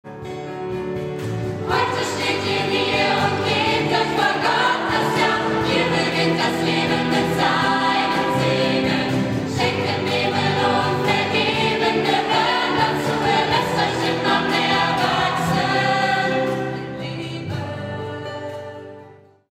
Notation: SATB
Tonart: G, E
Taktart: 4/4
Tempo: 112 bpm
Parts: 2 Verse, 2 Refrains, Bridge
Noten, Noten (Chorsatz)